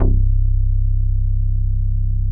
21SYN.BASS.wav